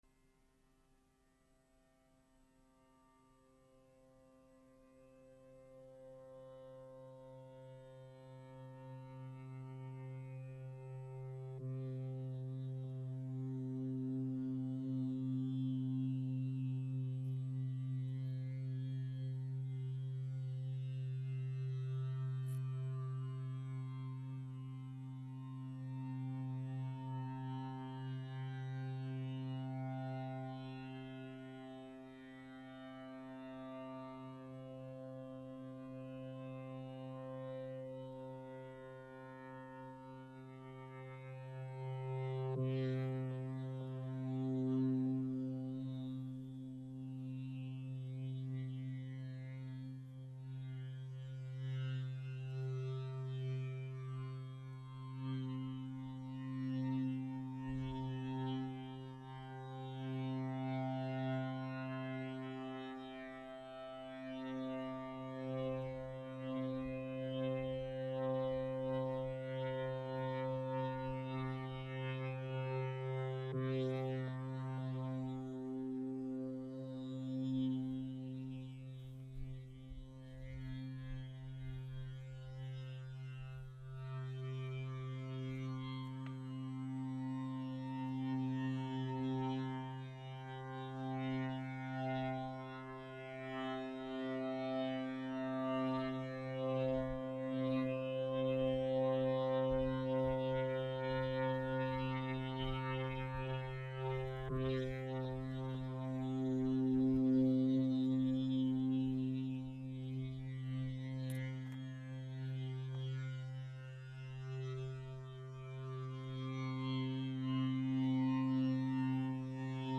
sound art